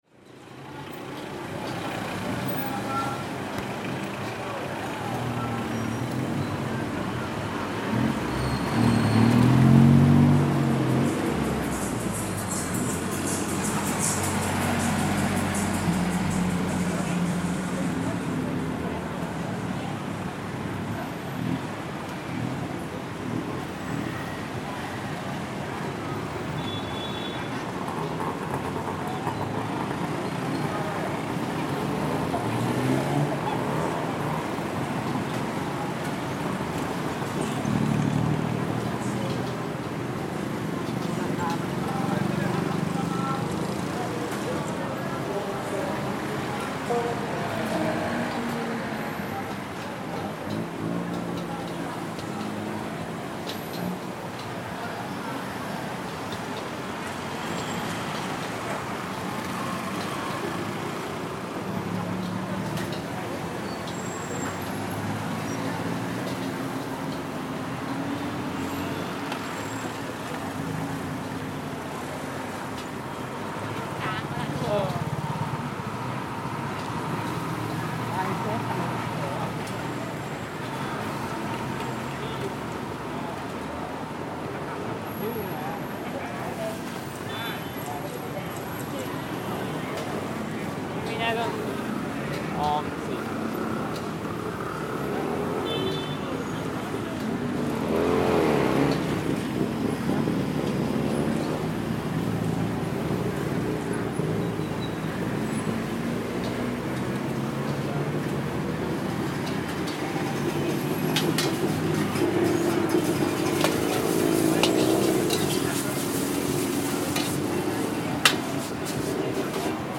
Exploring the sounds of the China Town district in Bangkok - this is the main artery road of the China Town district, the main centre for trading by the Chinese community since they moved from their old site in Bangkok some 200 years ago to make way for the construction of Wat Phra Kaew , the Grand Palace . Yaowarat Road is well known for its variety of foodstuffs, and at night turns into a large "food street" that draws tourists and locals from all over the city.